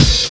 101 OP HAT.wav